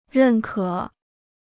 认可 (rèn kě): to approve; approval.